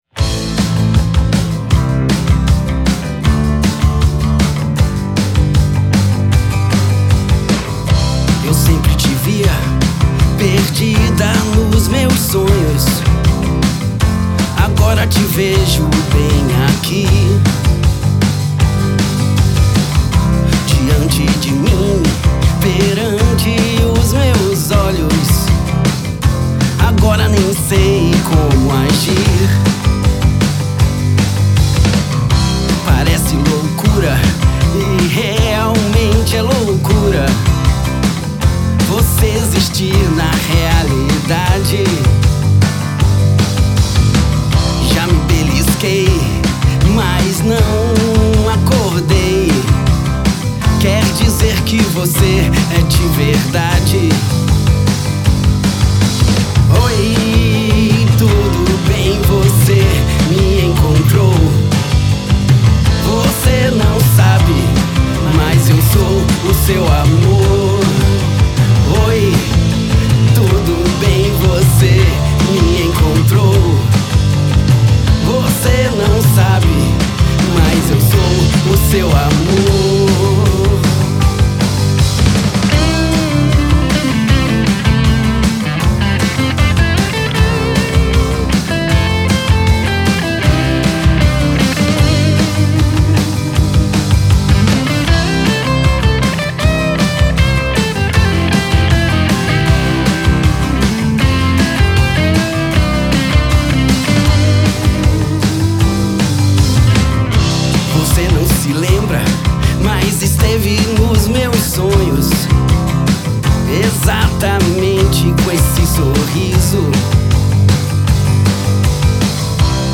todas guiadas pelo violão de cordas de aço